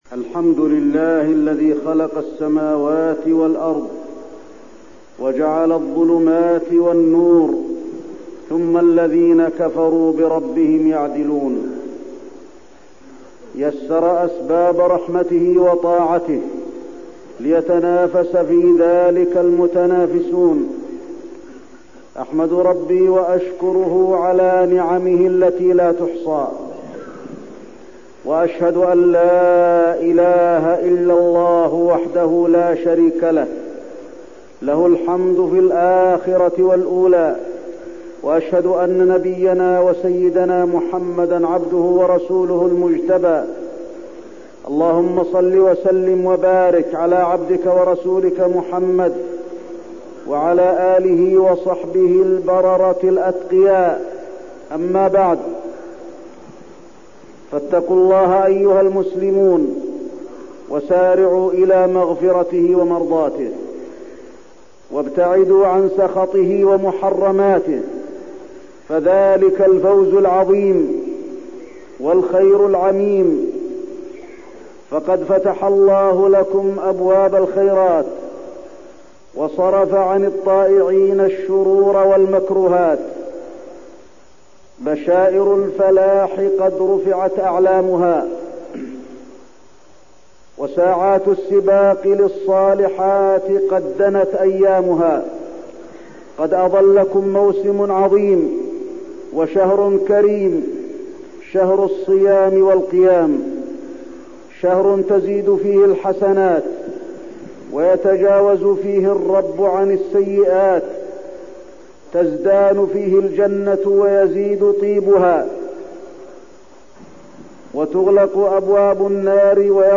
تاريخ النشر ٢٦ شعبان ١٤١٨ هـ المكان: المسجد النبوي الشيخ: فضيلة الشيخ د. علي بن عبدالرحمن الحذيفي فضيلة الشيخ د. علي بن عبدالرحمن الحذيفي الاستعداد لشهر رمضان